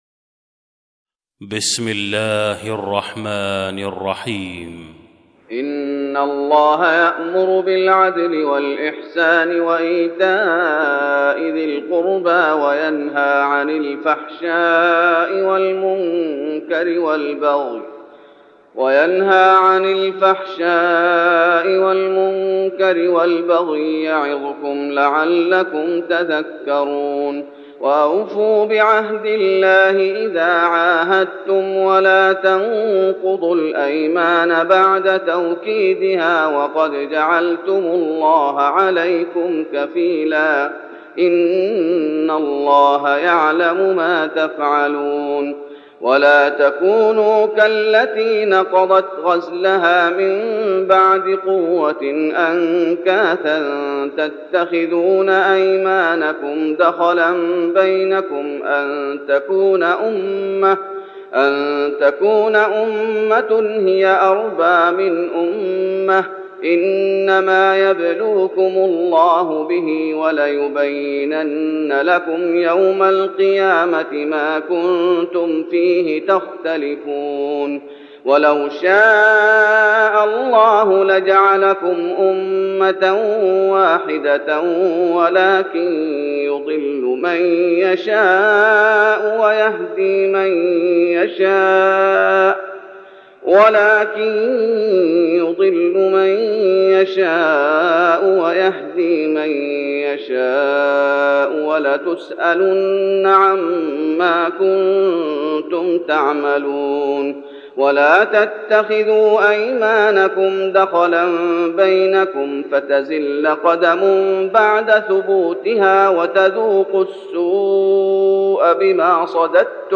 تهجد رمضان 1412هـ من سورة النحل (90-128) Tahajjud Ramadan 1412H from Surah An-Nahl > تراويح الشيخ محمد أيوب بالنبوي 1412 🕌 > التراويح - تلاوات الحرمين